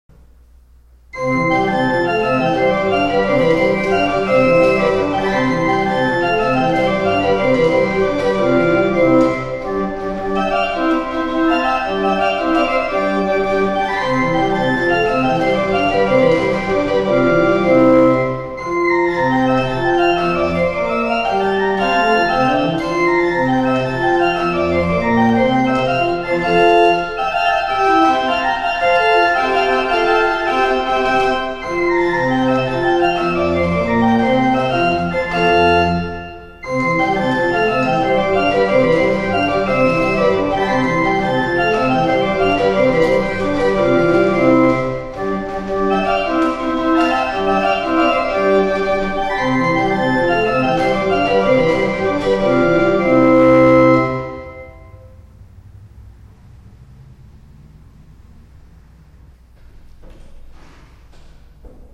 Old Norriton Presbyterian Church - Norristown, PA
To listen to Trip to Pawtucket by Oliver Shaw played on the Gedact 8', Bass 8' and Principal 4', click
The sound and general construction of the instrument is much more like an organ from the mid eighteenth century than the mid nineteenth century. The sound is very bright and intense but without any hint of harshness.